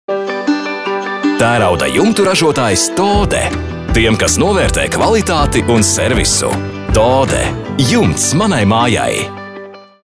RADIO REKLĀMAS